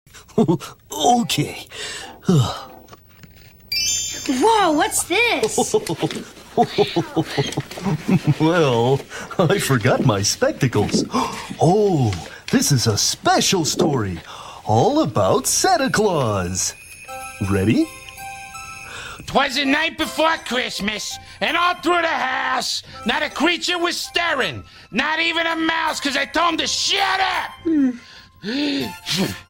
I kept the original underscore background music, but added Big Mean Carl’s voice from The Muppets.🤣🤣🤣🤣🤣🤣🤣🤣🤣🤣 Feel Free To Duet This Sound Effects Free Download.